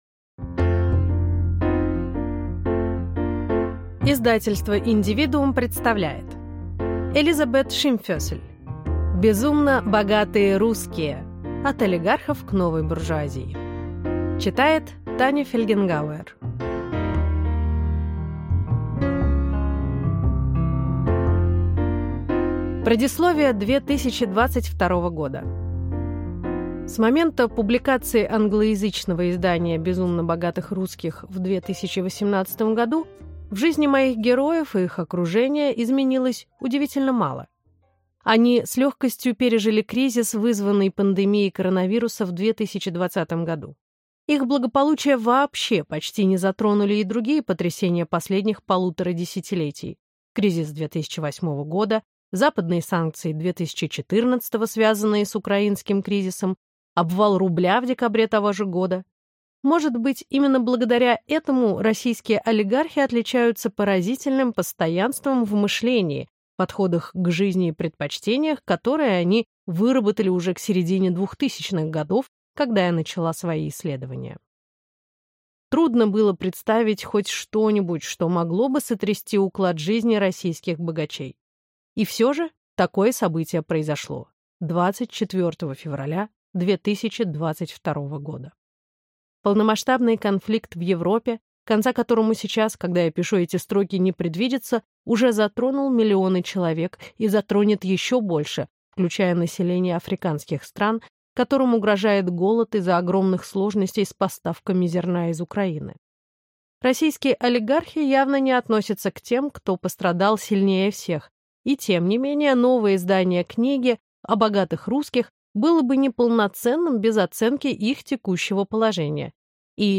Аудиокнига Безумно богатые русские. От олигархов к новой буржуазии | Библиотека аудиокниг